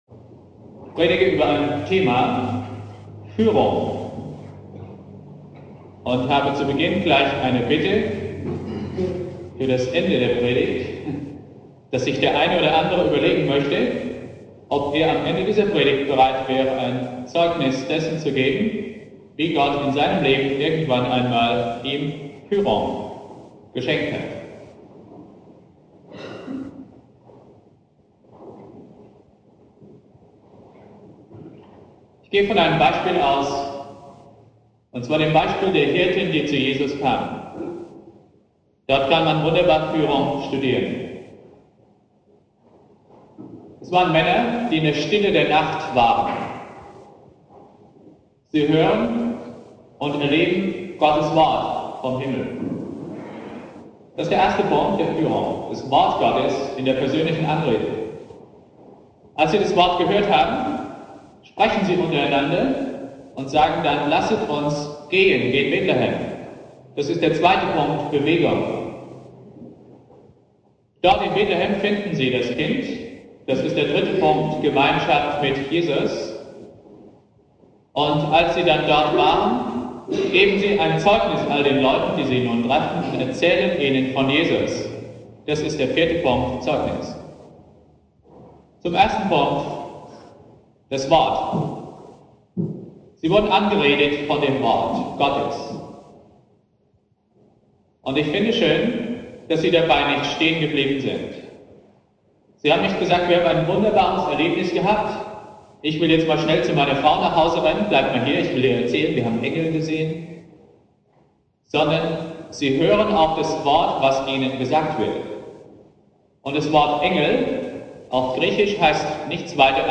Predigt
1.Weihnachtstag Prediger
"Führung" - 1. durch das Wort Gottes; 2. sich in Bewegung setzen; 3. Gemeinschaft mit Jesus; 4. Persönliches Zeugnis ==> spontane Zeugnisse am Ende der Predigt (vermutlich Weihnachten 1973) Predigtreihe: Themenpredigten Dauer: 23:34 Abspielen: Ihr Browser unterstützt das Audio-Element nicht.